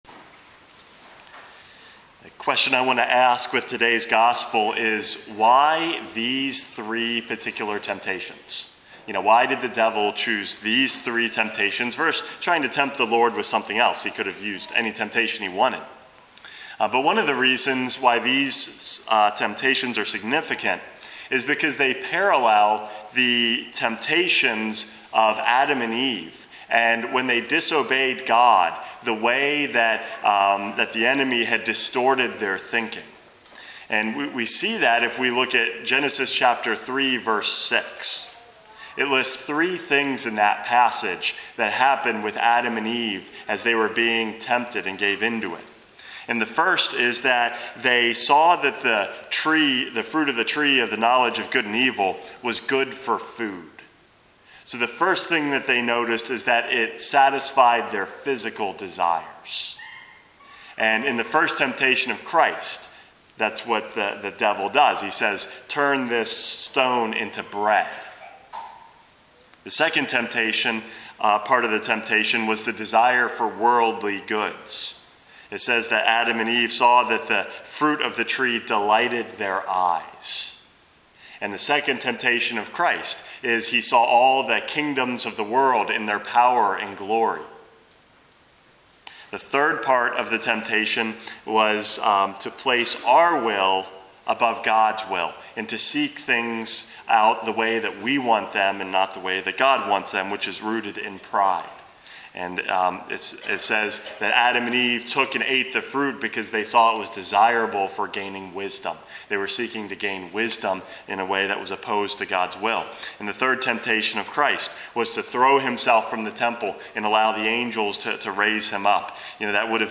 Homily #439 - Benefitting from Temptation